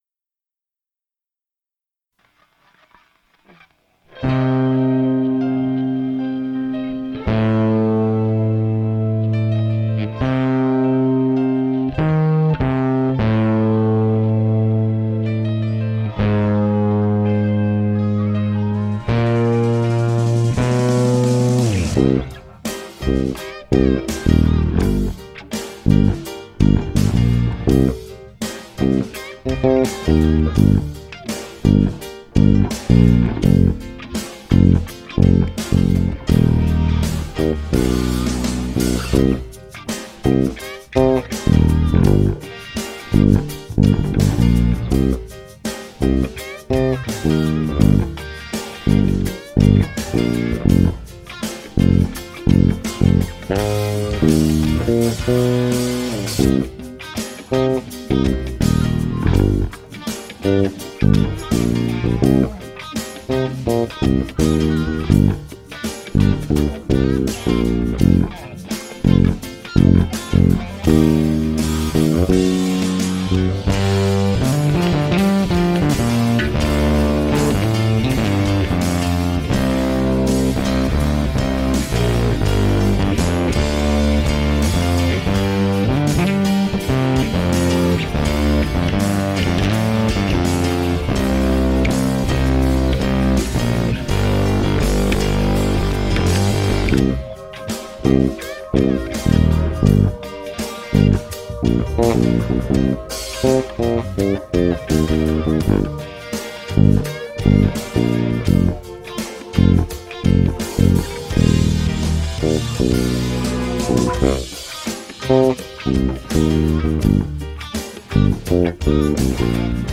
(instrumental)
Cover / Bass only